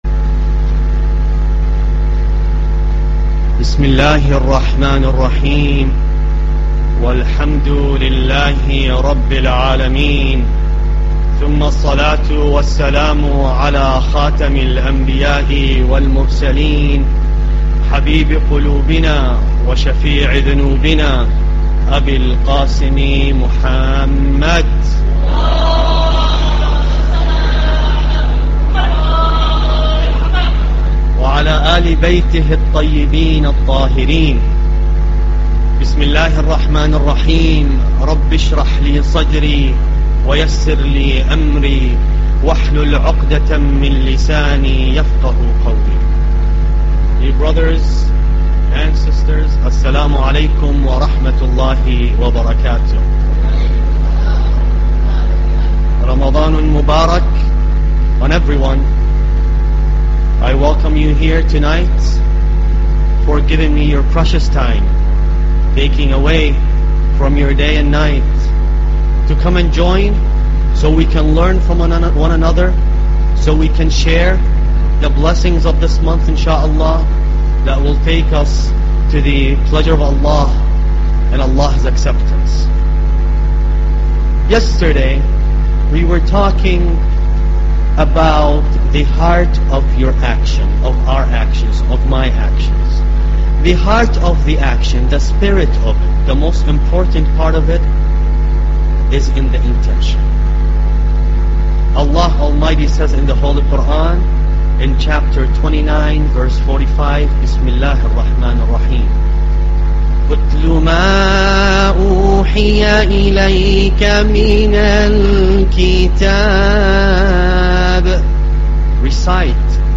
Lecture -2